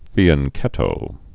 (fēən-kĕtō, -chĕtō)